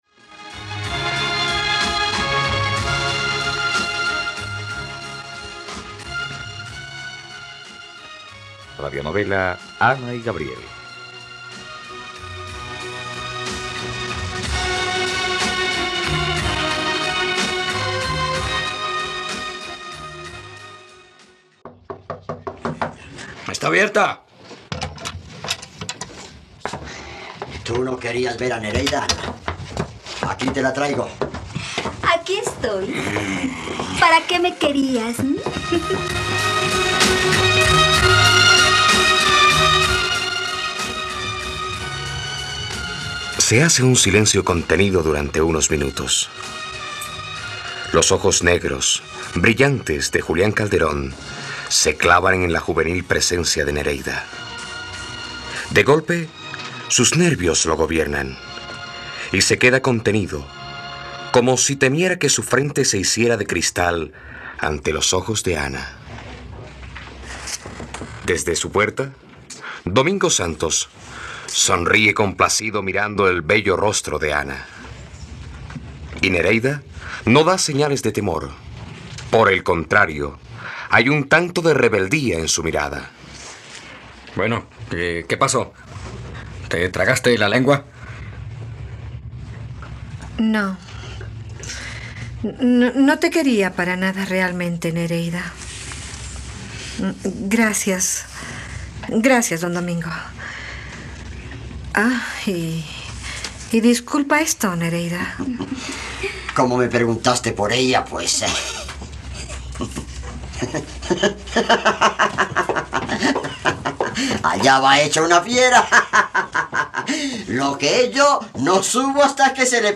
..Radionovela. Escucha ahora el tercer capítulo de la historia de amor de Ana y Gabriel en la plataforma de streaming de los colombianos: RTVCPlay.